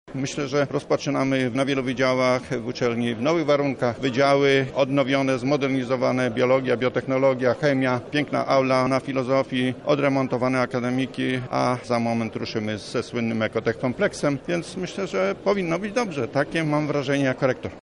– mówi prof. Stanisław Michałowski, rektor UMCS.